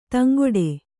♪ taŋgoḍe